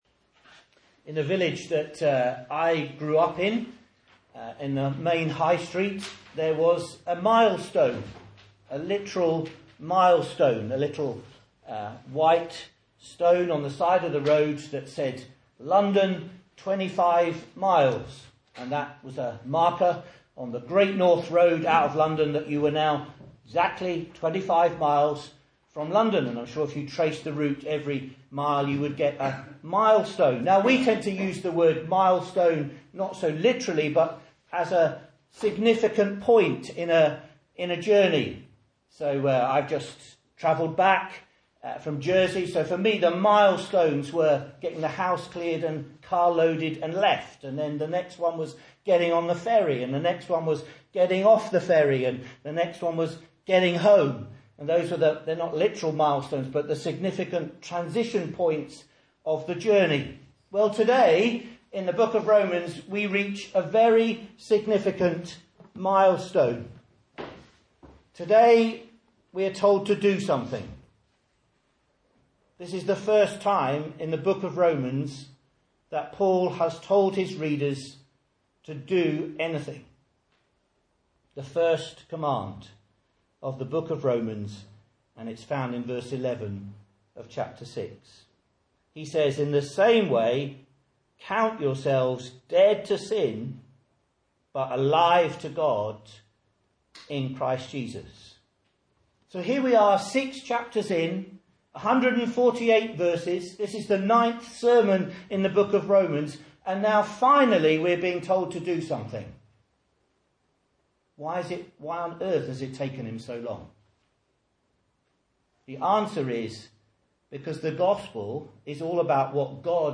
Message Scripture: Romans 6:1-14 | Listen